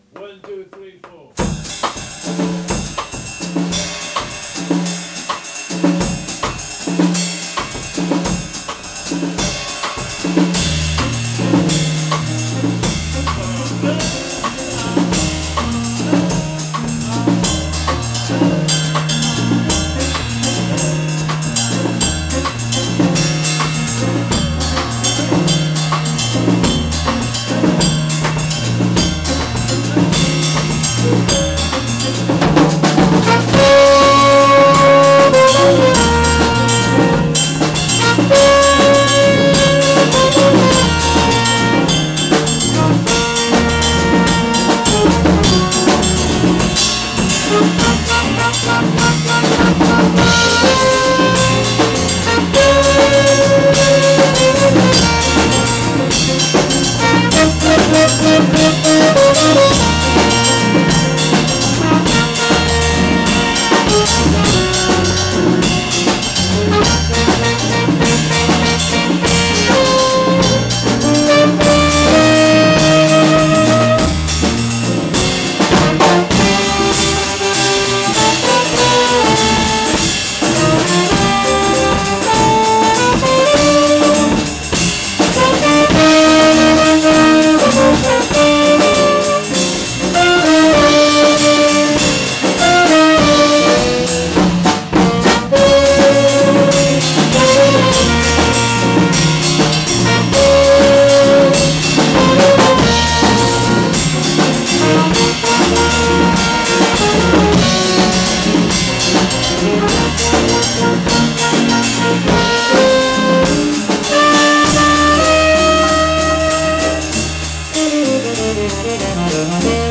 Playing ensemble 30.11.10 - out of this world.mp3